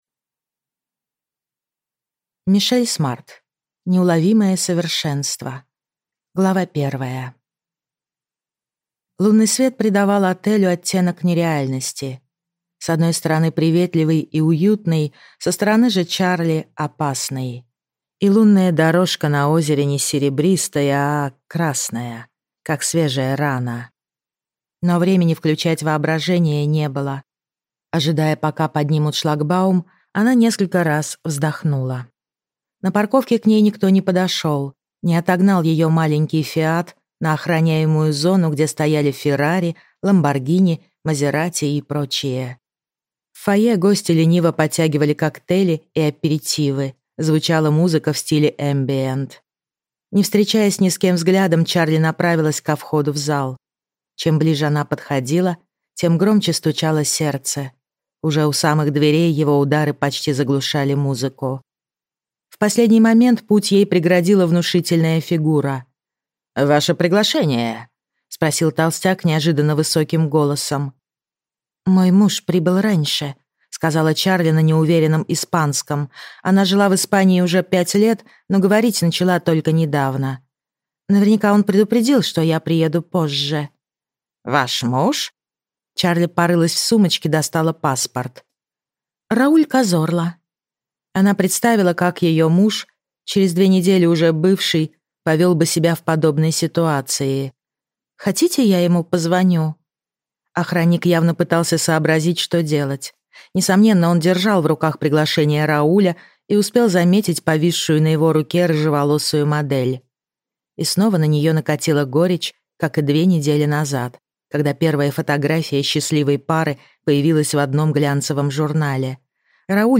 Аудиокнига Неуловимое совершенство | Библиотека аудиокниг